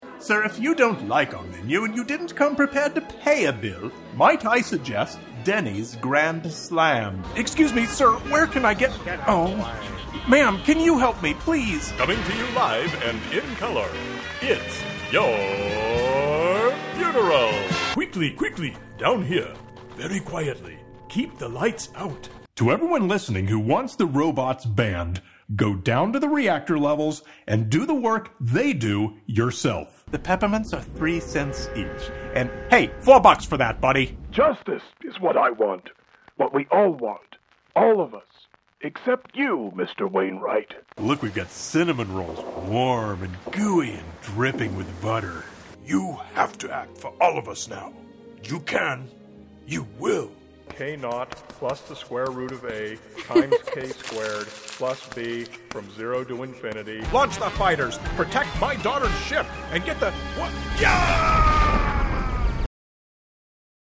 voice acting reels and stuff.